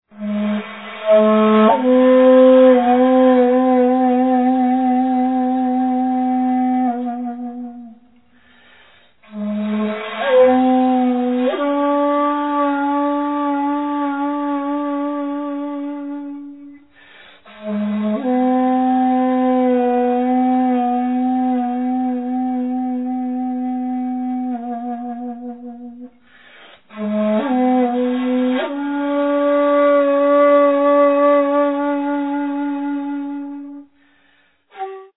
serene solo pieces